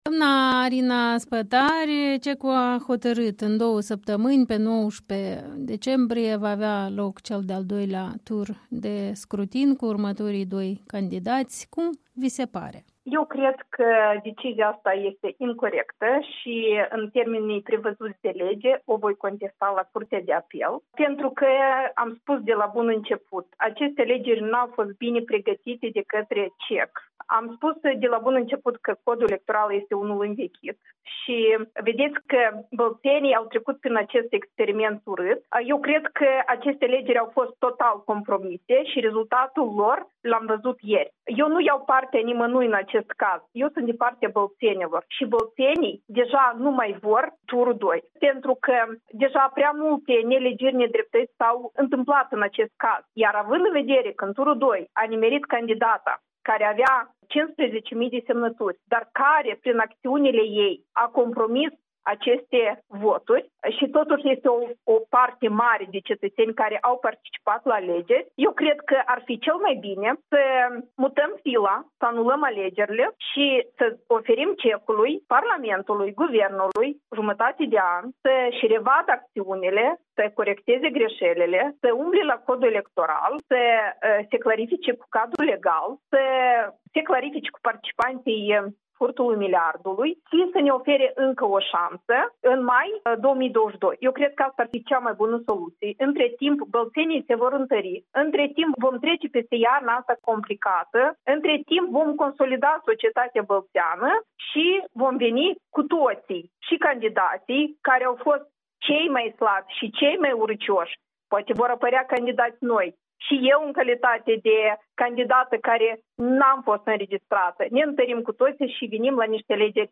Interviu cu Arina Spătaru, fostă parlamentară DA, de la Bălți